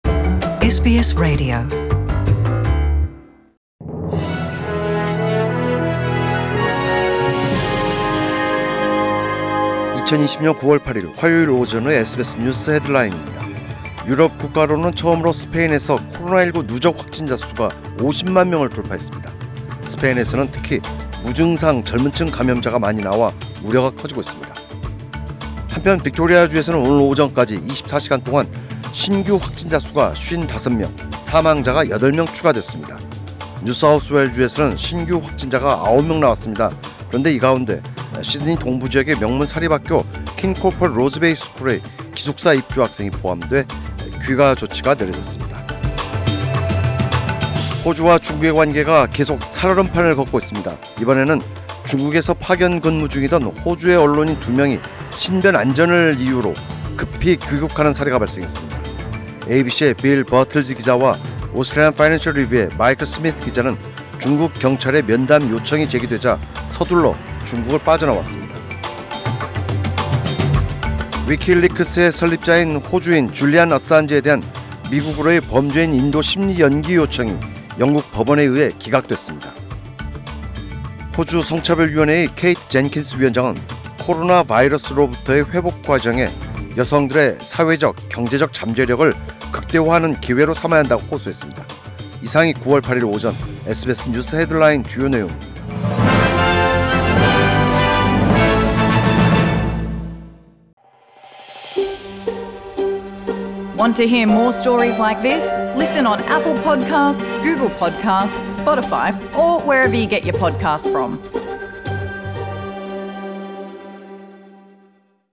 2020년 9월 8일 화요일 오전의 SBS 뉴스 헤드라인입니다.